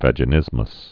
(văjə-nĭzməs)